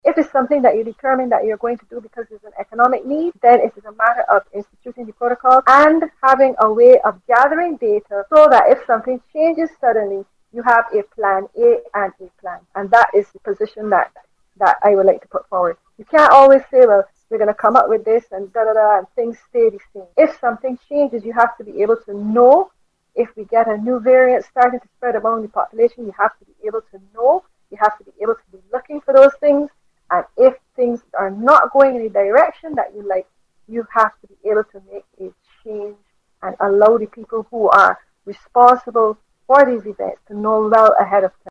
Speaking on VOB’s Down to Brasstacks programme she stressed that the best way to have a safe Crop Over is to increase vaccination and testing.